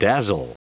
Prononciation du mot dazzle en anglais (fichier audio)